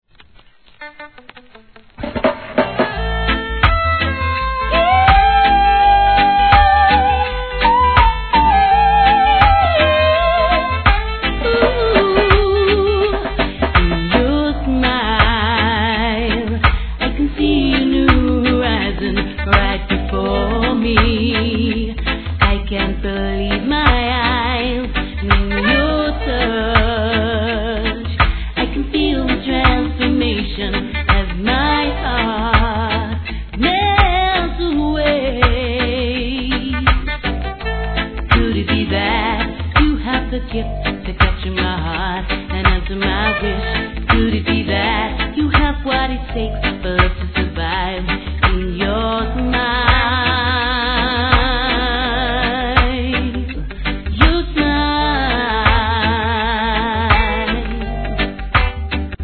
REGGAE
伸びのあるヴォーカルで聴かせます♪ No. タイトル アーティスト 試聴 1.